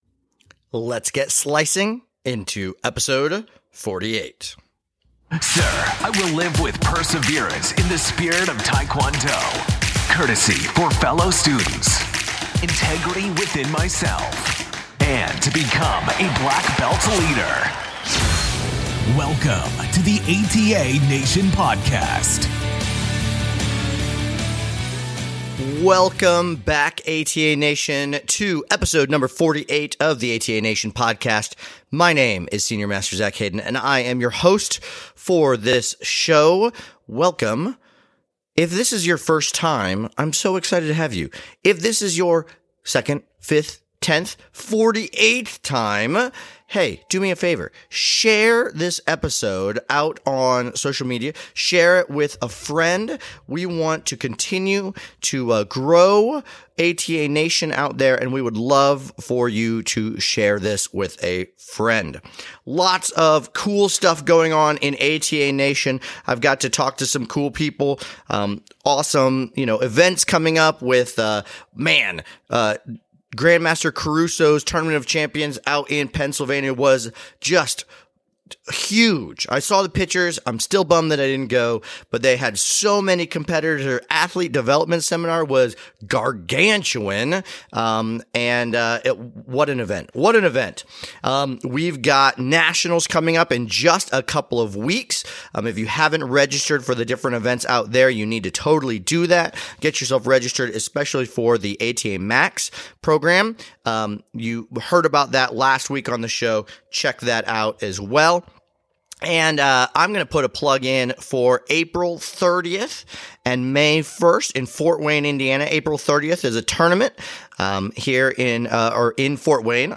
Have you heard of the ATA Traditional Gum Do Program? This week we interview one of the highest ranks in the program